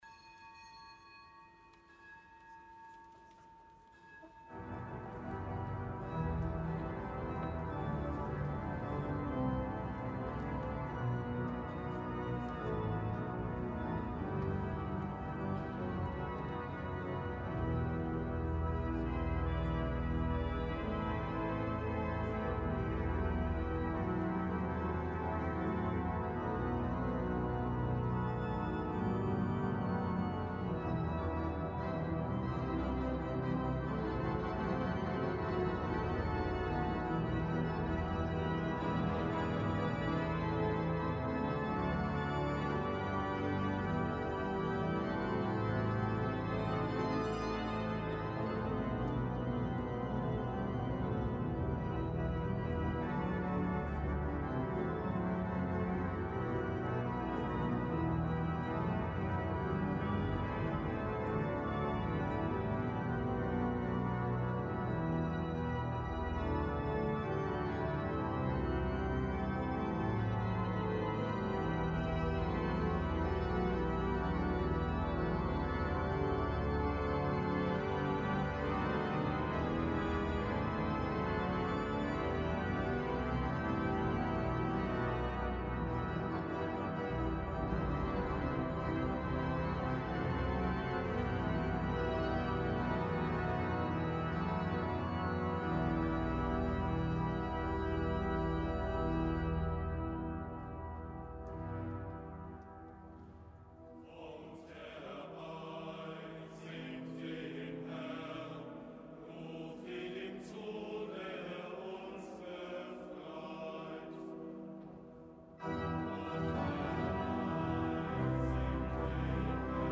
Die Männerstimmen des Domchores sangen